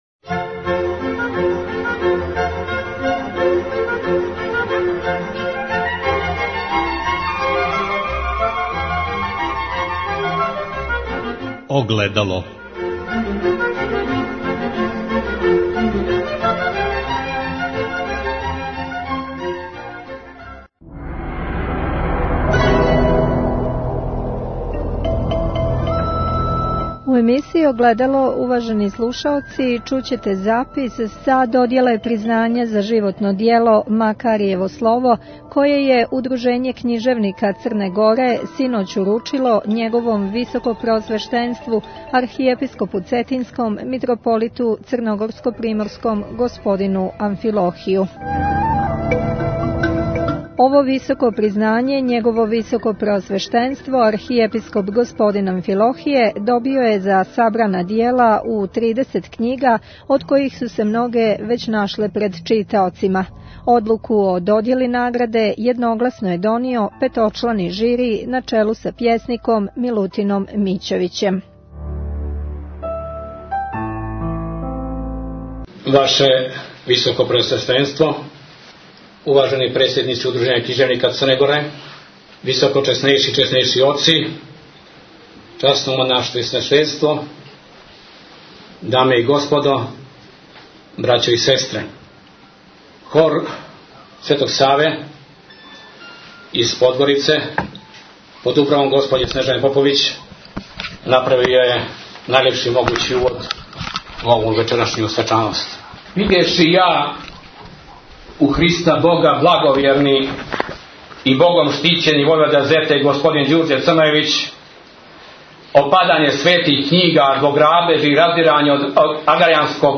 Слиједи запис са додјеле овог признања.